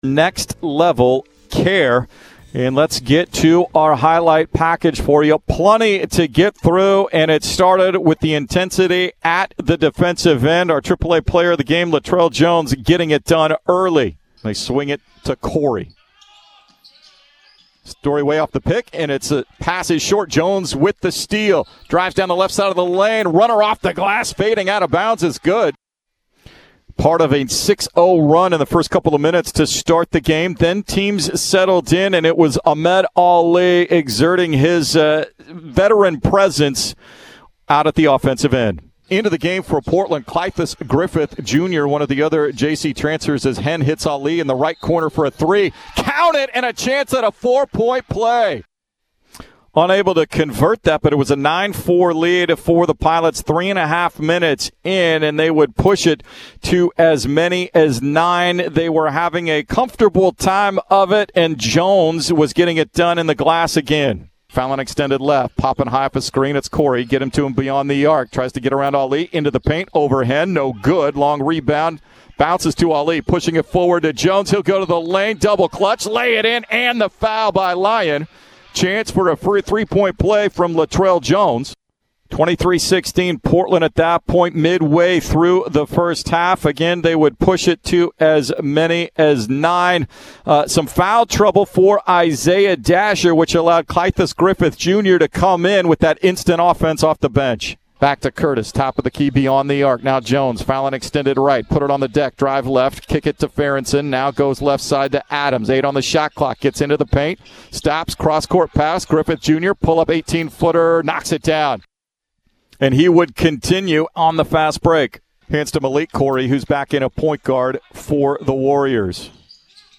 November 28, 2020 Postgame radio highlights from Portland's 83-73 win against William Jessup on Nov. 28, 2020 at the Chiles Center.
Men's Basketball Post-Game Highlights